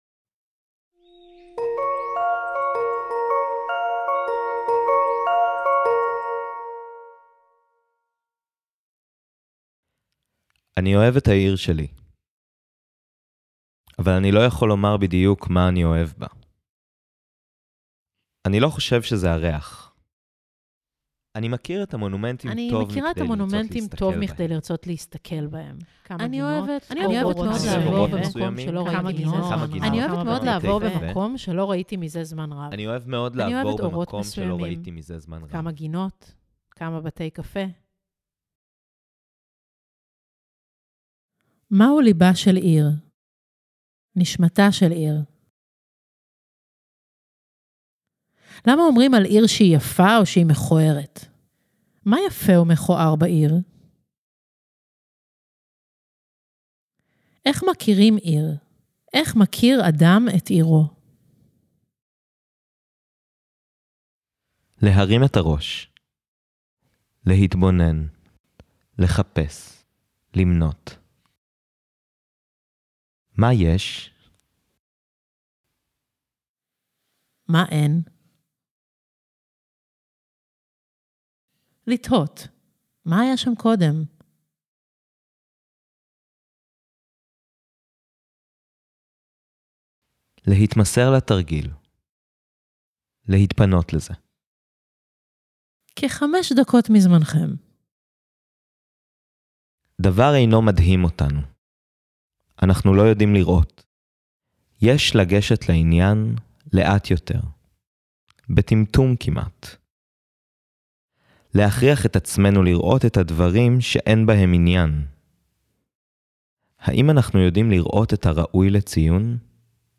עבודת סאונד: לפענח פיסת עיר